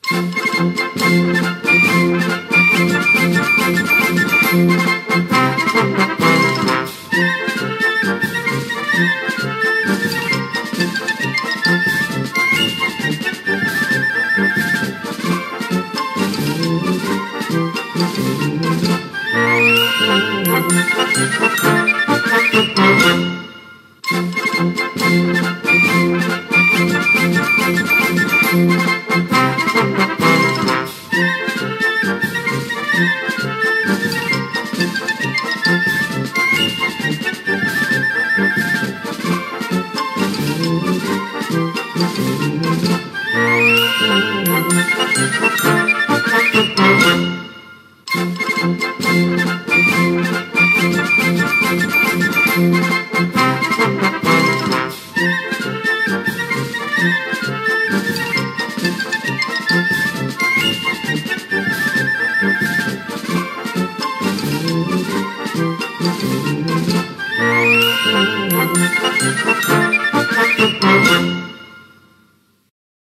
Versió de la sintonia de l'emissora